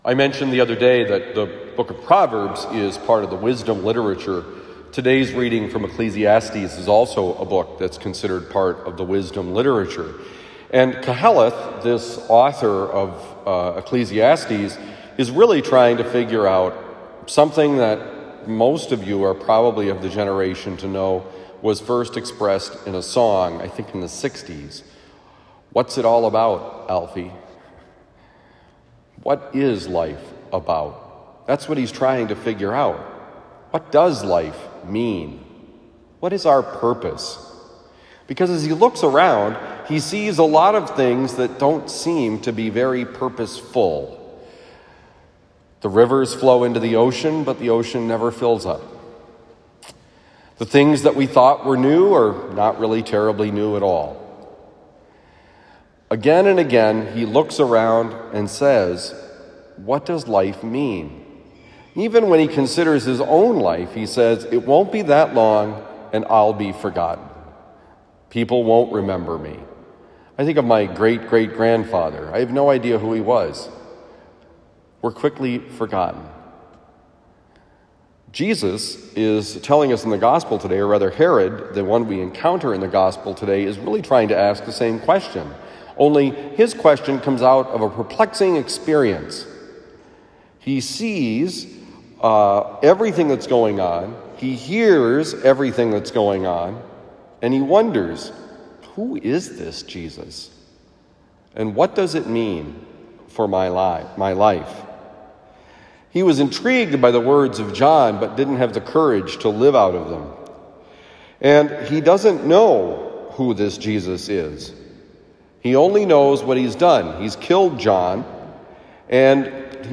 How to find meaning and purpose in your life: homily for Thursday, September 22, 2022
Given at Christian Brothers College High School, Town and Country, Missouri.